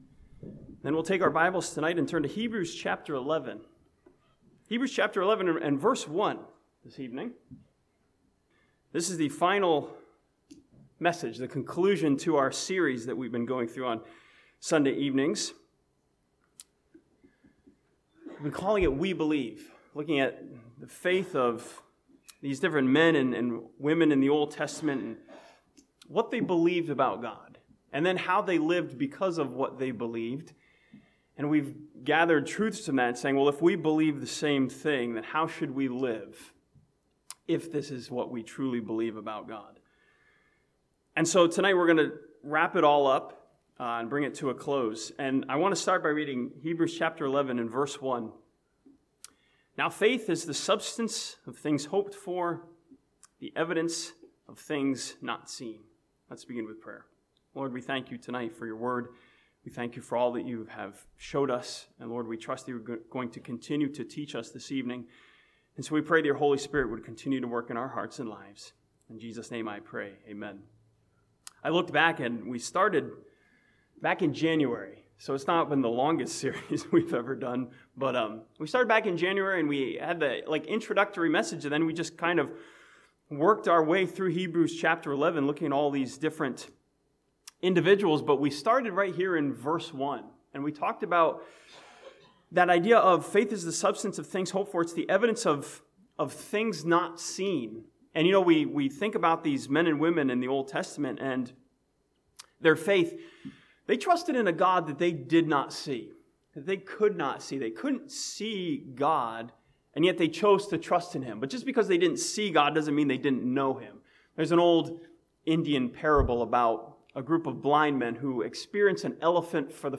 This sermon from Hebrews chapter 11 sums up this series by encouraging the believer to look unto Jesus.